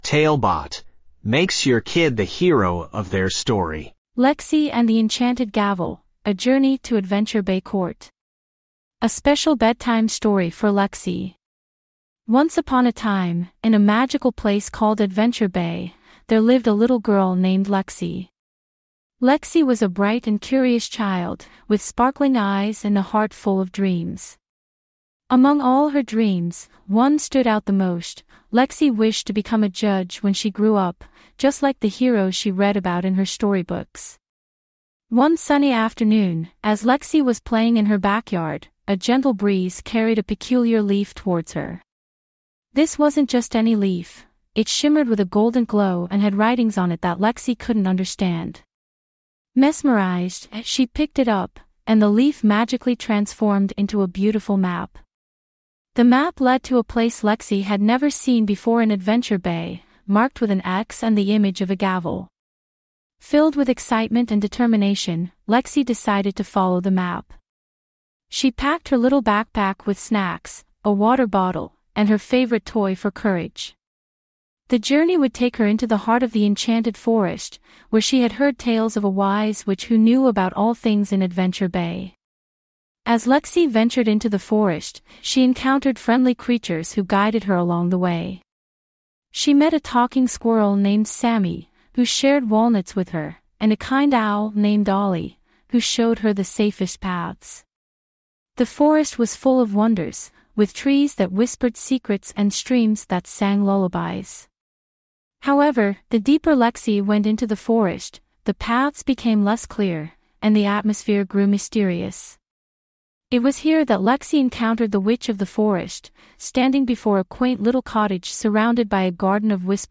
5 minute bedtime stories.
TaleBot AI Storyteller